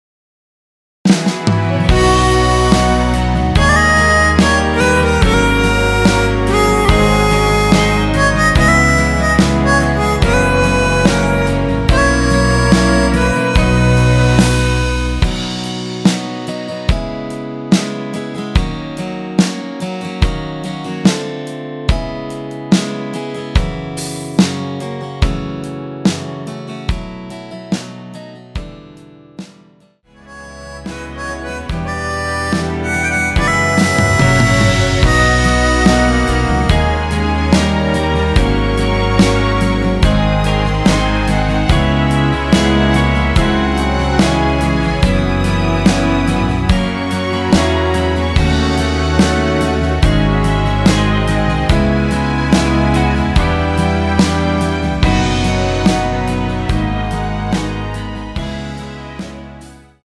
원키에서 (-1)내린 MR 입니다.
◈ 곡명 옆 (-1)은 반음 내림, (+1)은 반음 올림 입니다.
앞부분30초, 뒷부분30초씩 편집해서 올려 드리고 있습니다.